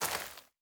added stepping sounds
DirtRoad_Mono_03.wav